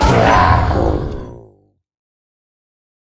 Minecraft Version Minecraft Version snapshot Latest Release | Latest Snapshot snapshot / assets / minecraft / sounds / mob / ravager / death3.ogg Compare With Compare With Latest Release | Latest Snapshot